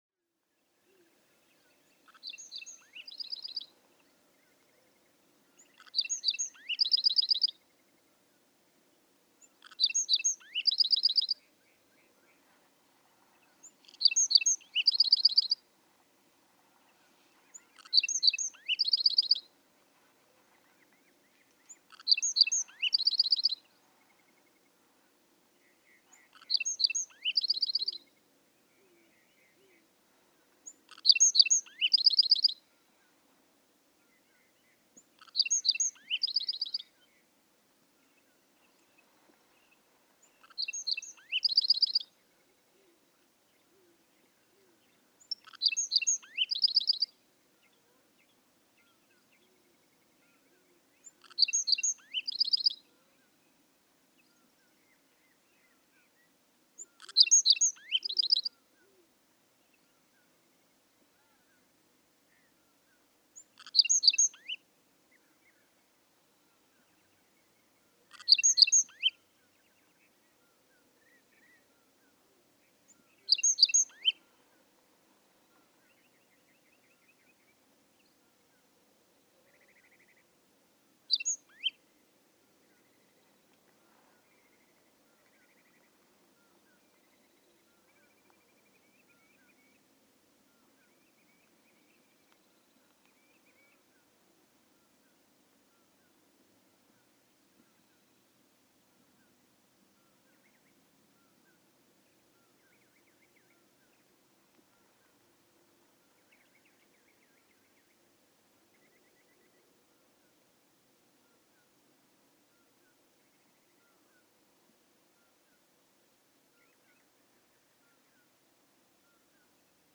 Black-throated sparrow
Dawn singing: He warms up by repeating one song type over and over, then with seemingly more enthusiasm resumes at 5:47, after a longish pause, to alternate two different songs. Hear the common poorwill in the distance?
Joshua Tree National Park, California.
425_Black-throated_Sparrow.mp3